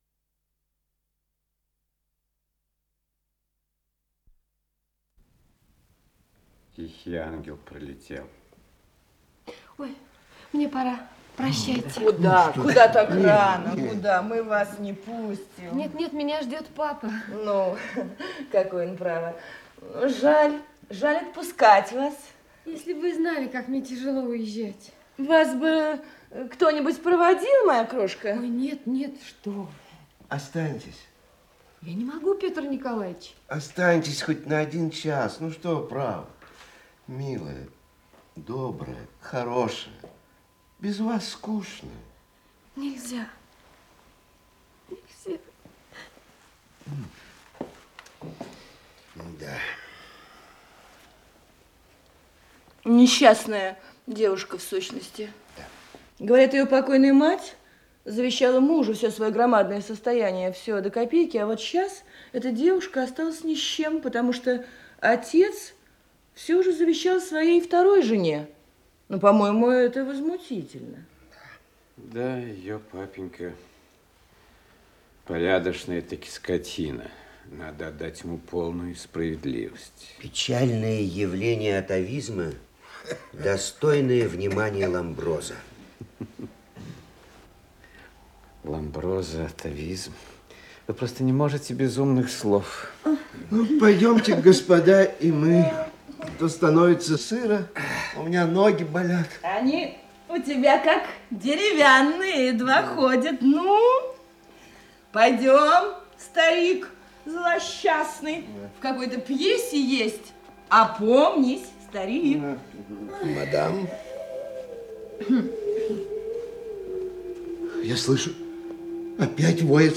Исполнитель: Артисты МХАТ СССР им. Горького
Спектакль